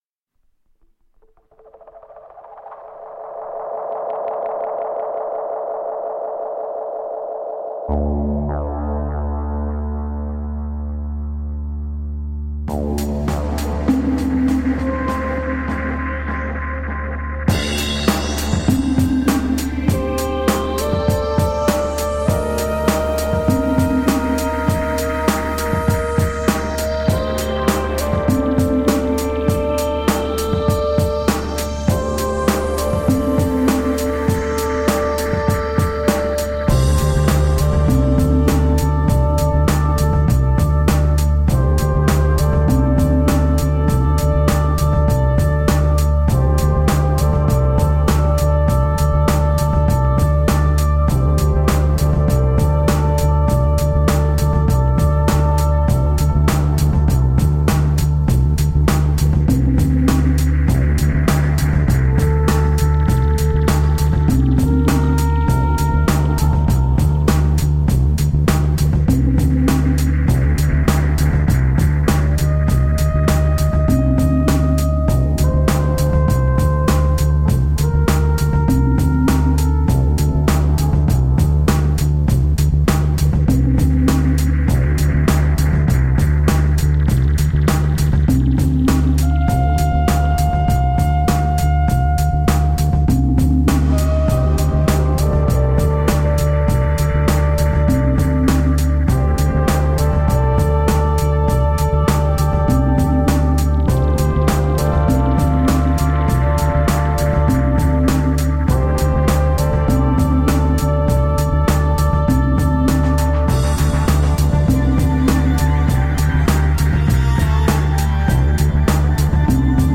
Jazz/dub intense electro funk.